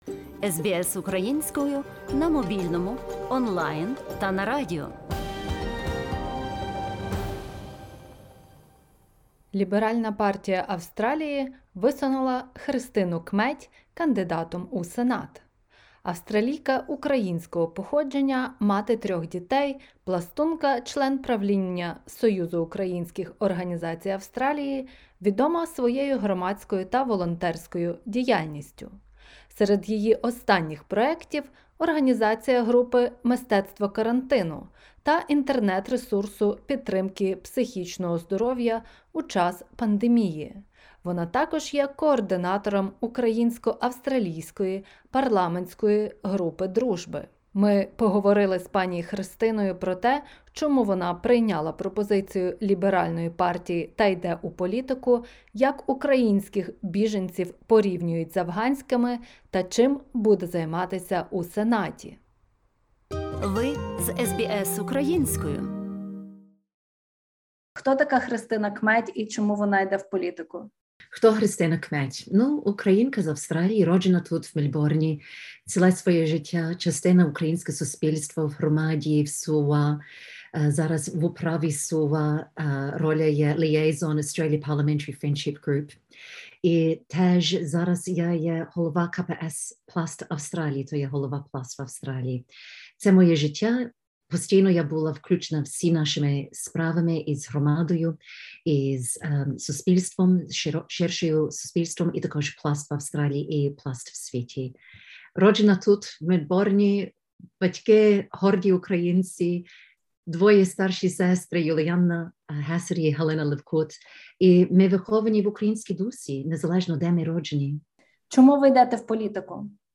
Інтерв’ю